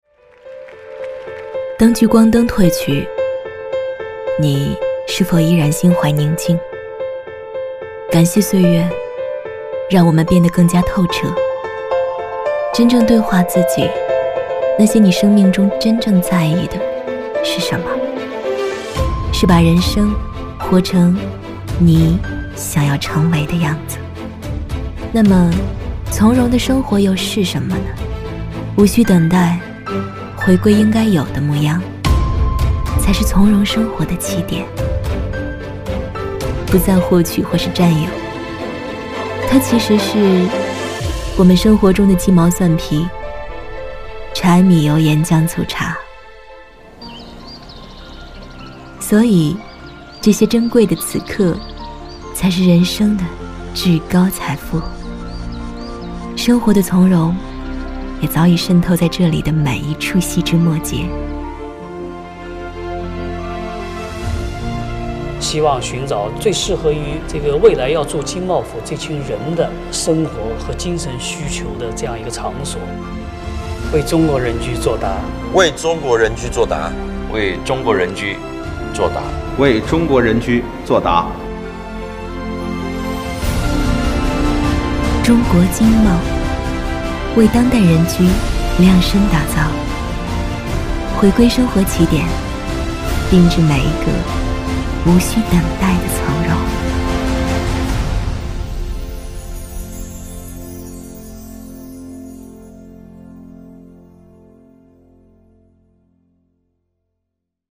女19-宣传片·地产大气走心·龙华金茂府 寻找生活的答案
女19甜配音 v19
女19-宣传片·地产大气走心·龙华金茂府-寻找生活的答案.mp3